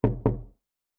Wood Door Knock A.wav